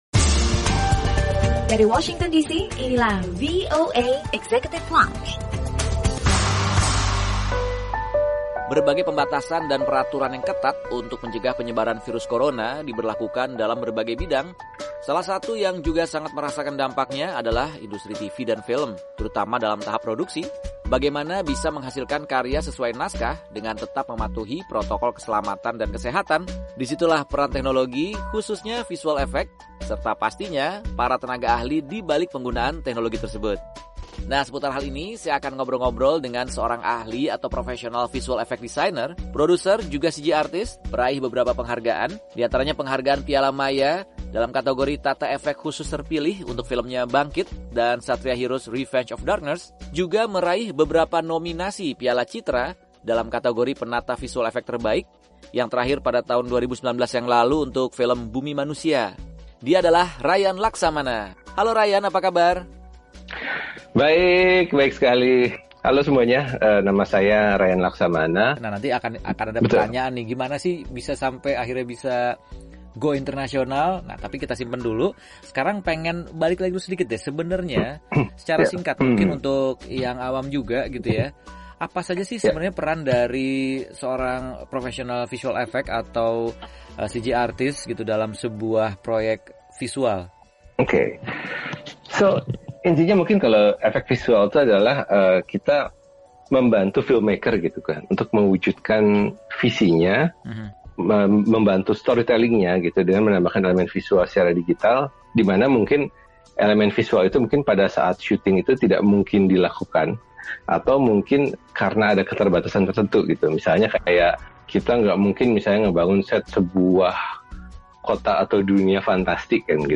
Obrolan bersama seorang prefesional Visual Effect Designer dan CG Artist Indonesia yang sudah sering bekerja menggarap proyek-proyek berskala internasional